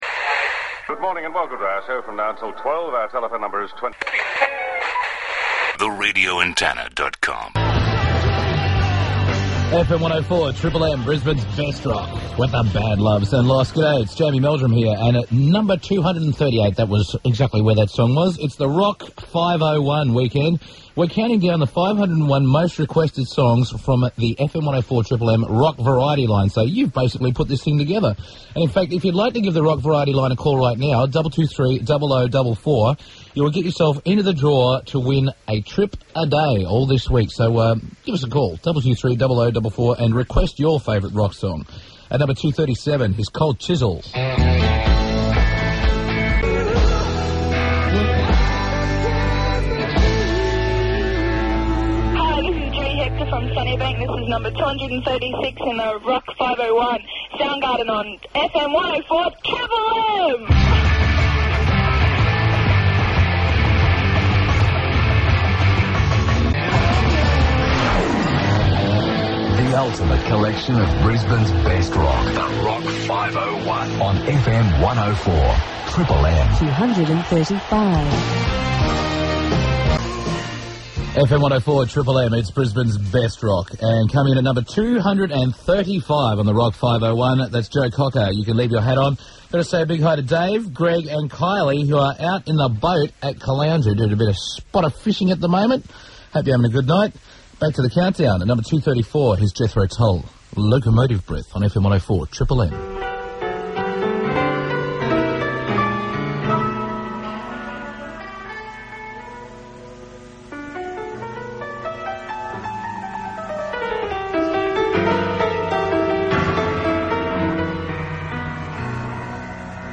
Scoped includes sweepers